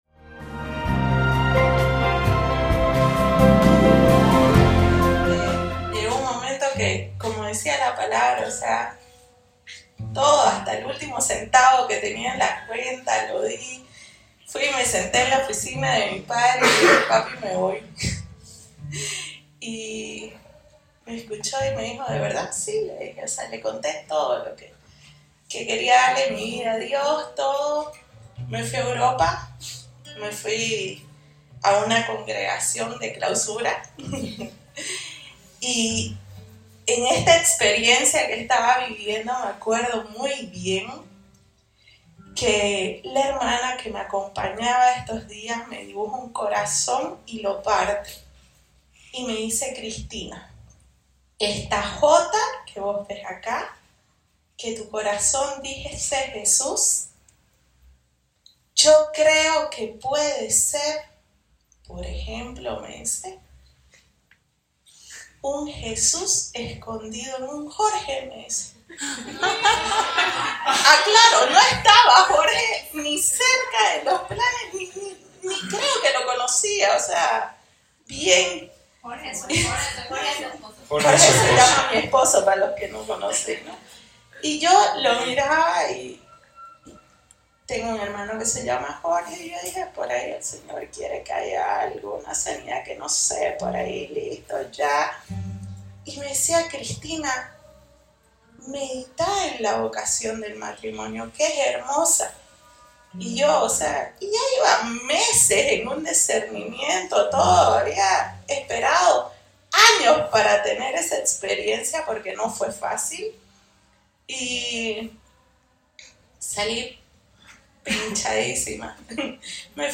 Predicadora